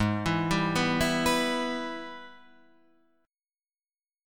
AbmM7#5 chord